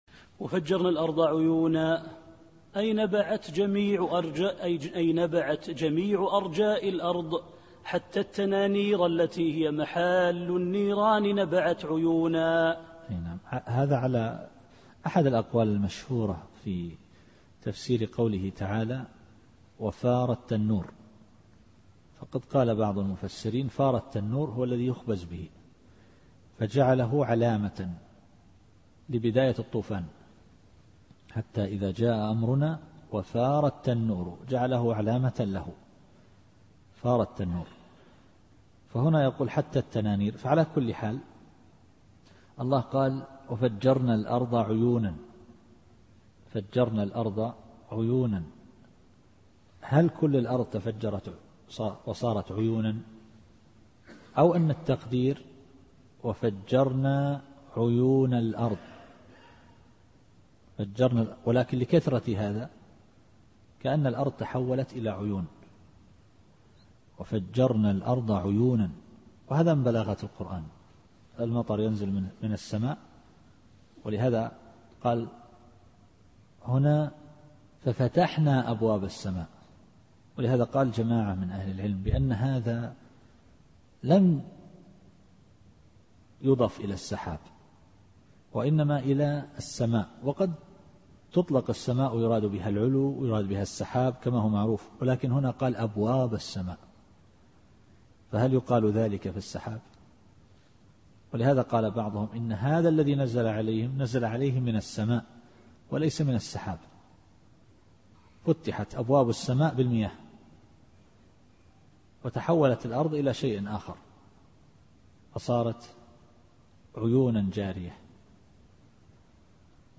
التفسير الصوتي [القمر / 12]